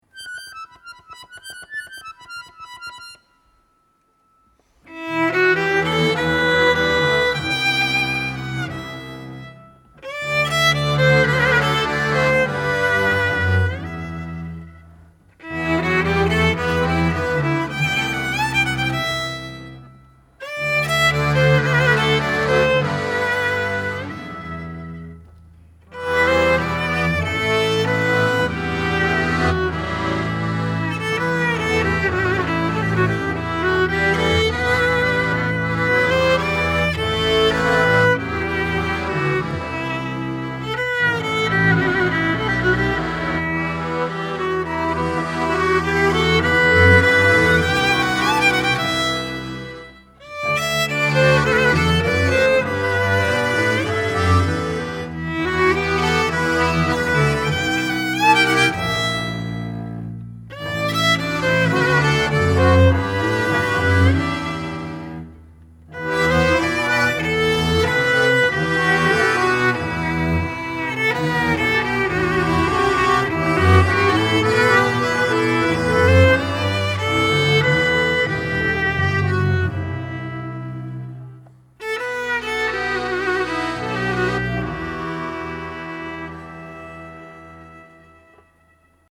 Violin
Button Accordion, Piano
Bass Cello, Tilinca
Genre: Klezmer.